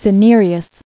[ci·ne·re·ous]